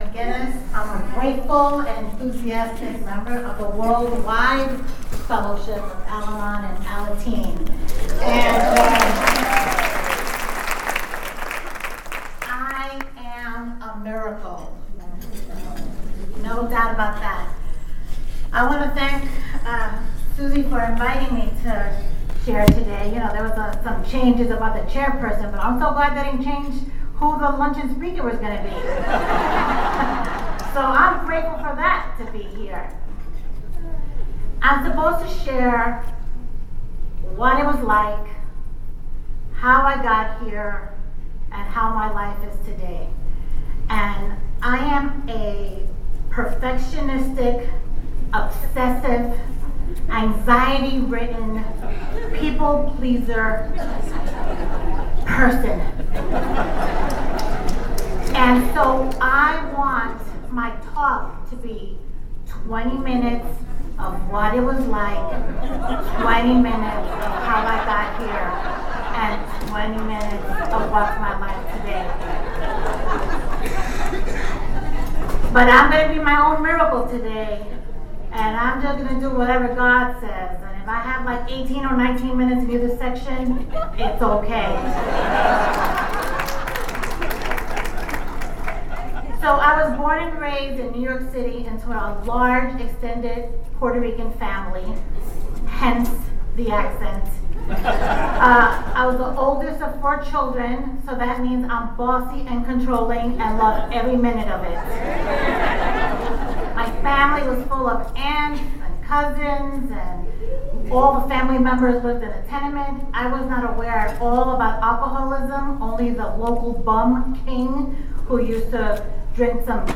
45th Southern California Al-Anon Family Groups Convention